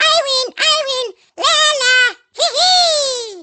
One of Baby Mario's first place sound clips from Mario Kart Wii
VoiceBabyMarioGoalTop.oga.mp3